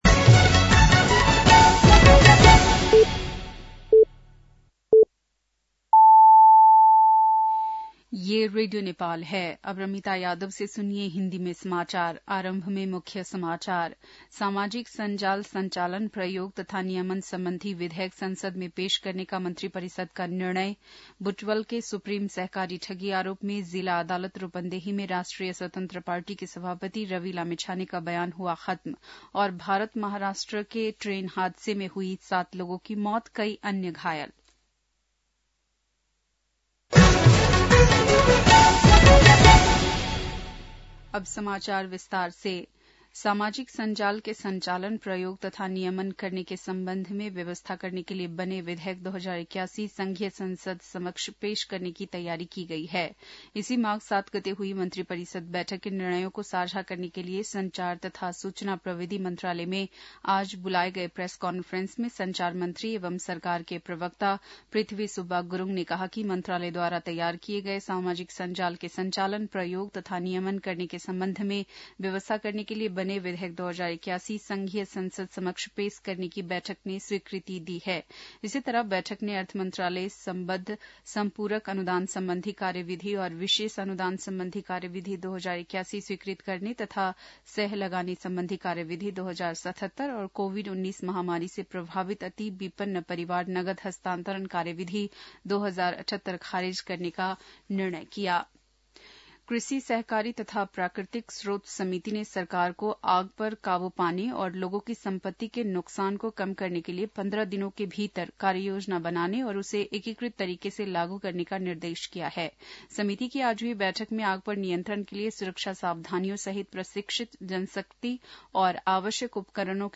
बेलुकी १० बजेको हिन्दी समाचार : १० माघ , २०८१